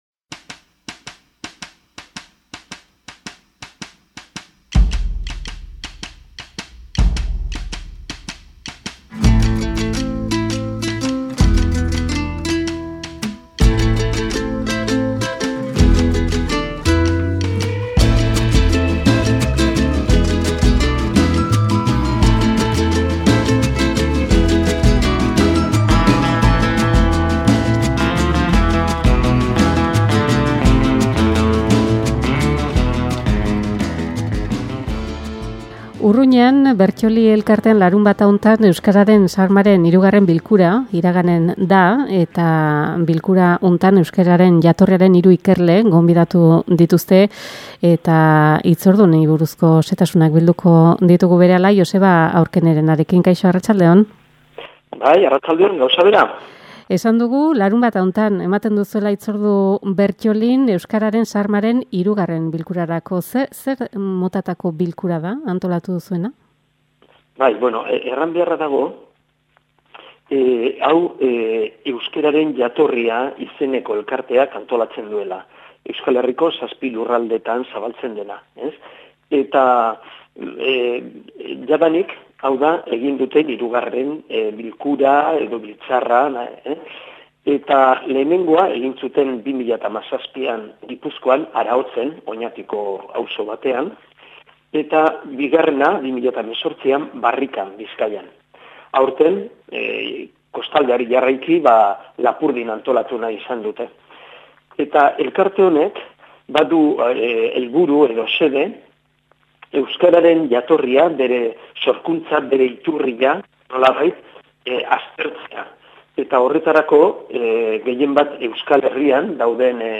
Irratien tartean Urruñara jo dugu, larunbatean bertako Berttoli elkartean izango den Euskeraren xarmaren 3.bilkuraren berri izateko, Antxeta irratiaren eskutik. Euskararen jatorriari erreparatuko diote bilkura honetan hiru ikerlarik.